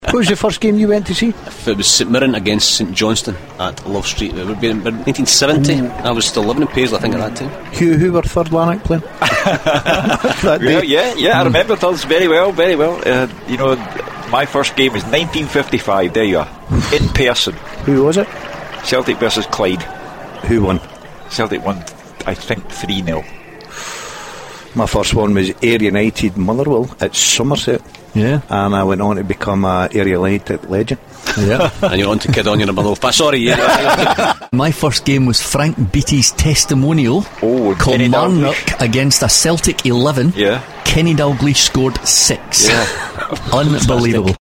Clip taken from Clyde 1 Superscoreboard 24th January 2015.